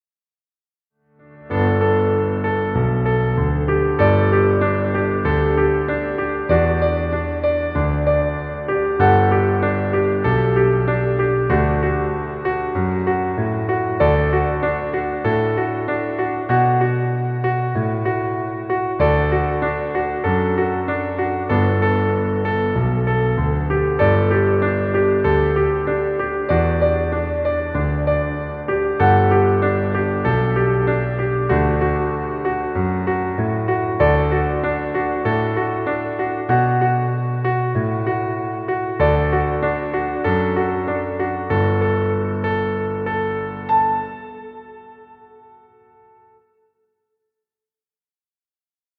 Piano music. Background music Royalty Free.